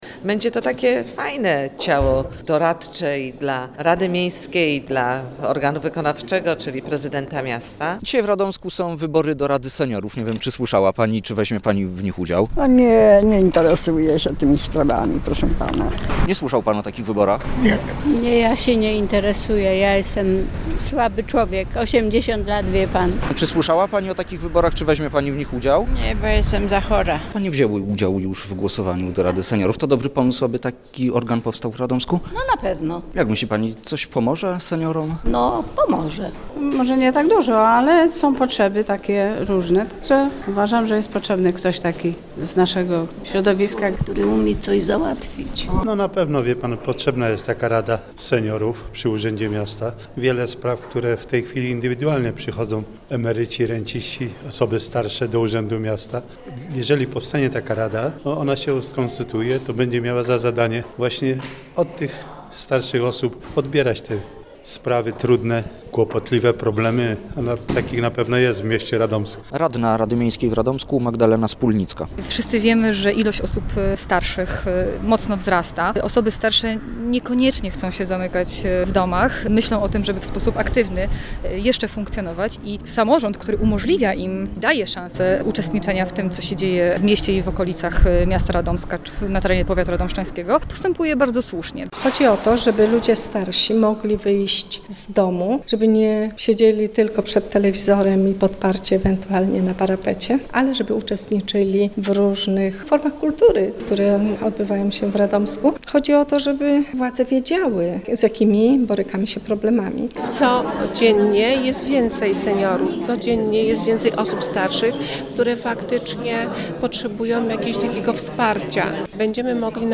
Wysłuchaj relacji z dnia wyborów: Nazwa Plik Autor Wybory do Rady Seniorów audio (m4a) audio (oga) Warto przeczytać Pieniądze na sport w województwie łódzkim. 12 obiektów przejdzie remont 9 lipca 2025 Niż genueński w Łódzkiem.